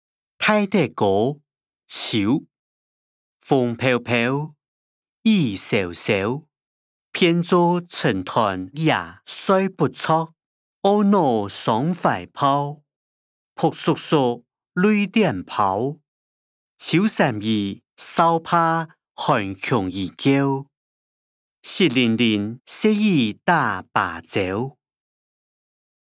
詞、曲-大德歌‧秋音檔(四縣腔)